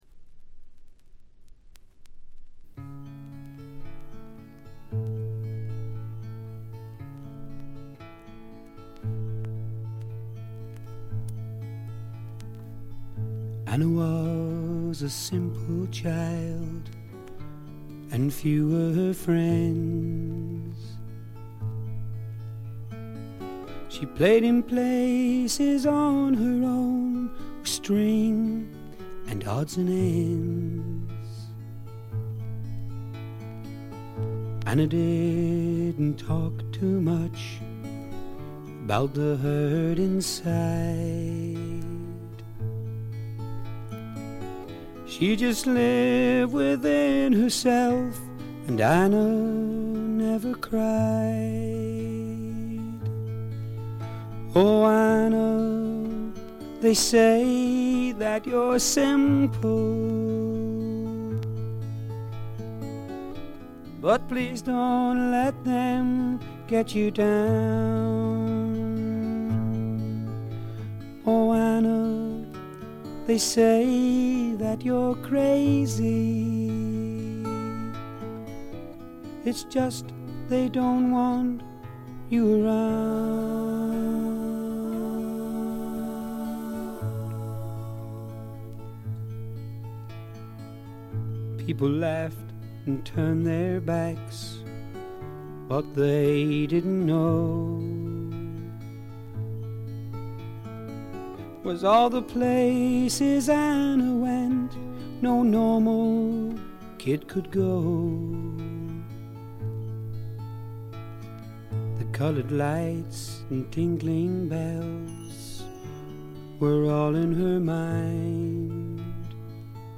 これ以外はところどころでチリプチ。
英国のフォーキーなシンガー・ソングライター
弾き語りに近いような控えめでセンスの良いバックがつく曲が多く、優しい歌声によくマッチしています。
試聴曲は現品からの取り込み音源です。